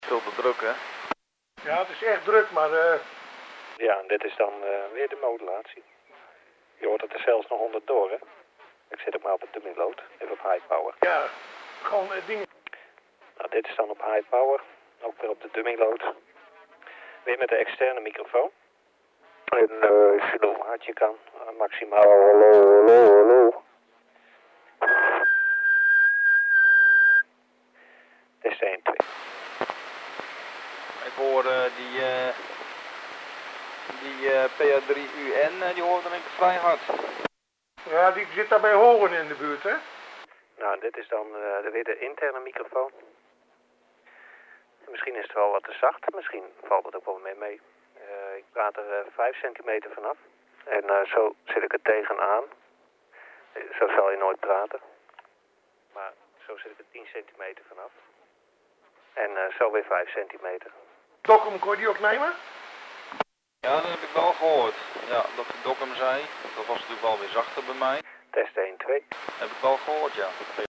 This is a Dutch spoken example. First you hear some normal base-stations (with some noise). After a few seconds, you hear me talking on the UV-5RE with an external microphone (no brand). I’m using a dummy load and my signal is recognizable as the one with no noise. After 40 seconds, I’m talking in the internal microphone of the transceiver.
Conclusion: the modulation sounds somewhat thin and not very loud when talking 10cm away from the microphone. It also has some kind of equalized sound to it (DSP generated).
Modulation-test-first-with-external-non-Baofeng-mike-later-with-internal-mike.mp3